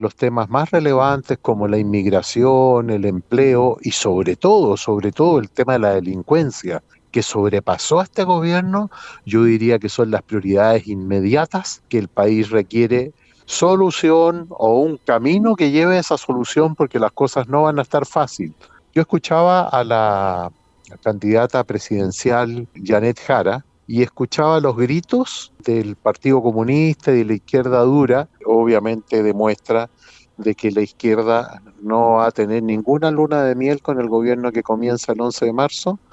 Cifras que no dejaron indiferente a los senadores de la zona, que en conversación con La Radio, dieron cuenta de sus proyecciones y análisis, a solo minutos de conocerse que el republicano será el próximo presidente.